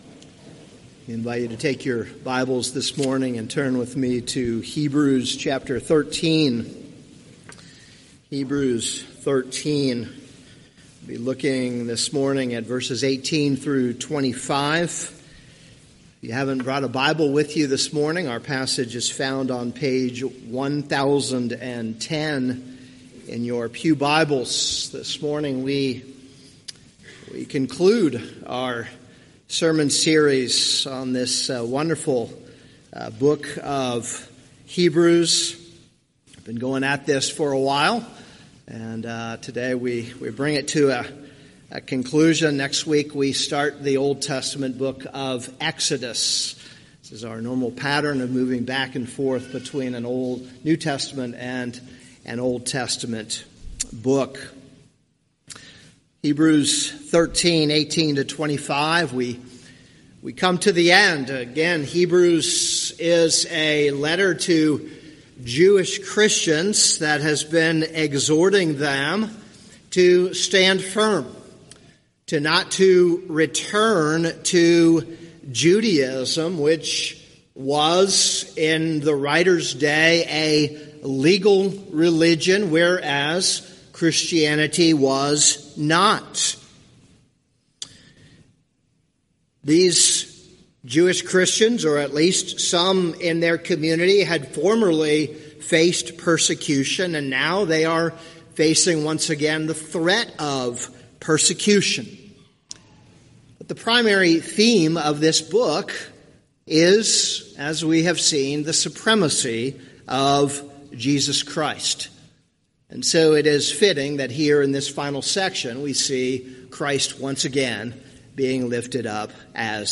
This is a sermon on Hebrews 13:18-25.